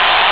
1 channel
crowd9.mp3